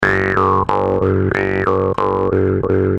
Его вибрирующие, гипнотические тона идеальны для медитации, релаксации и погружения в этническую атмосферу.
Якутский варган и его звучание